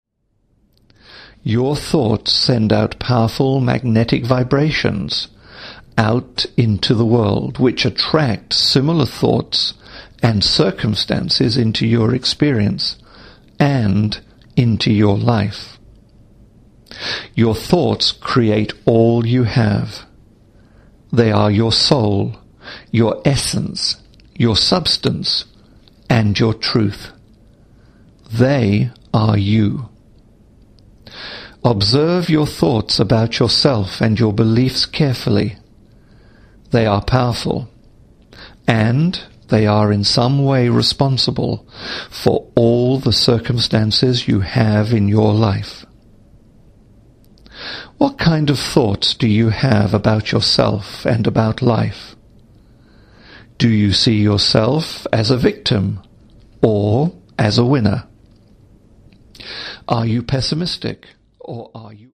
Audio Induction; Audio Affirmations;
No Music Ambience; No Nature Ambience
Lay back and be guided into a pleasant state of deep relaxation through voice only.
The Positive Change program contains only voice, and you will be guided through the session.